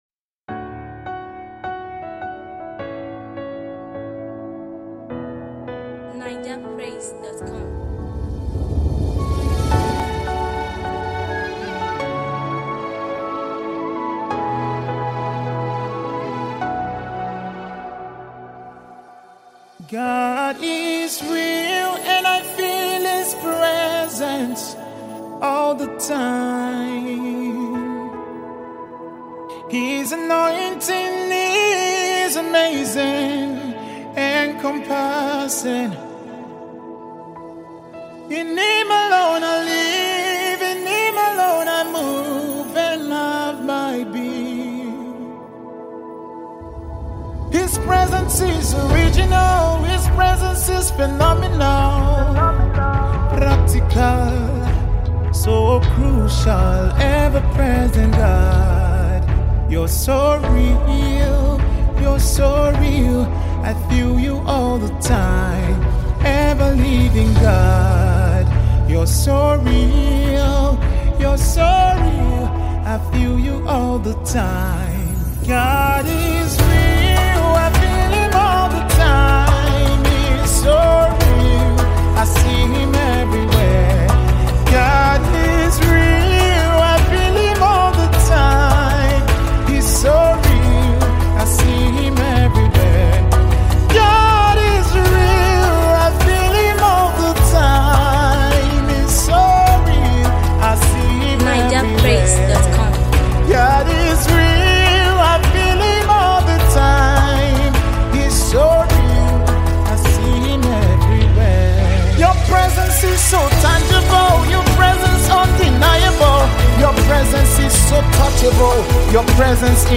Renowned gospel music minister
song of praise